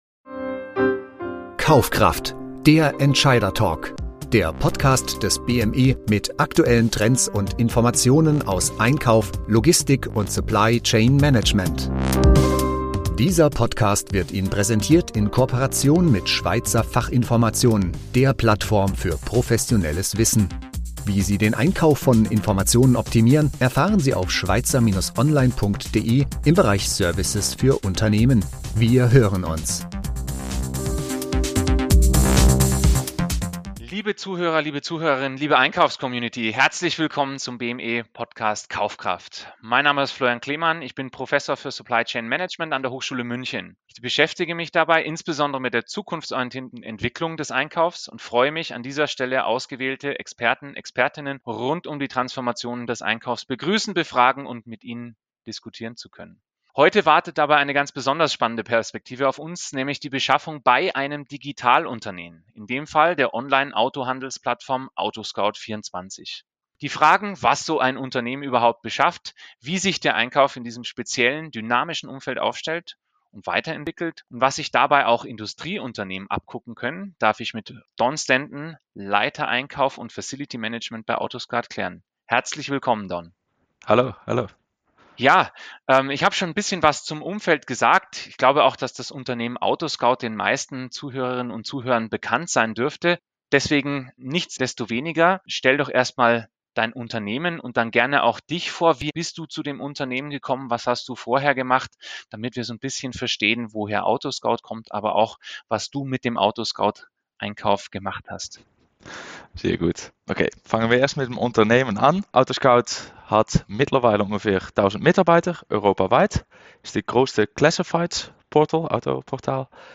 Die Diskussion beleuchtet die Ausgangslage des Unternehmens und analysiert, welche Art von Beschaffung in einem digitalen Umfeld entscheidend ist. Das Interview vertieft nicht nur das Verständnis für die Hintergründe des Unternehmens, sondern taucht auch in die sich wandelnde Dynamik ein. Besonders im Fokus steht die Rolle der Automatisierung im Transformationsprozess der Einkaufsabteilung.